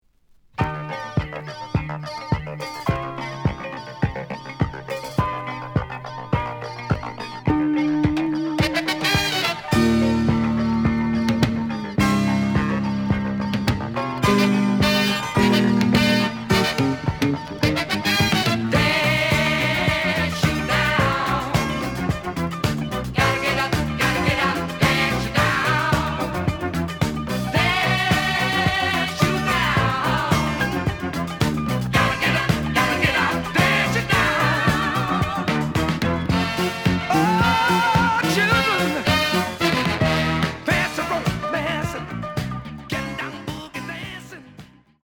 試聴は実際のレコードから録音しています。
●Genre: Disco
EX-, VG+ → 傷、ノイズが多少あるが、おおむね良い。